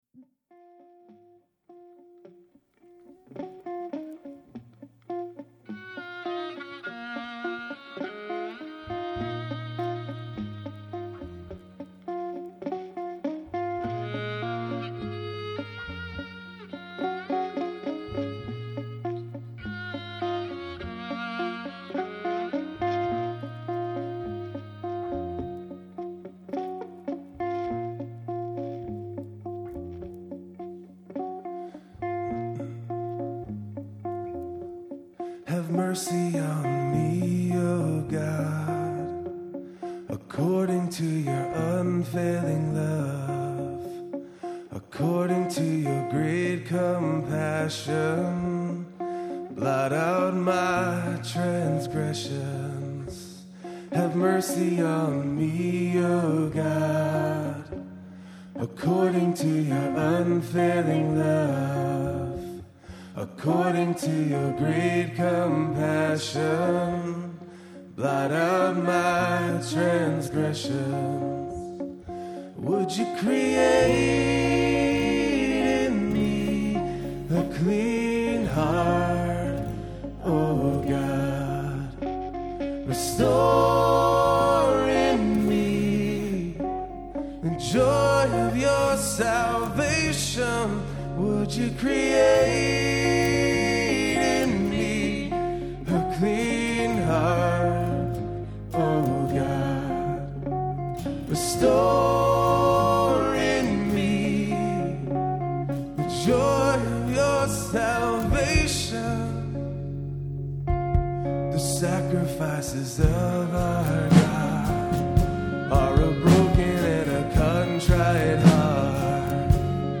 Performed live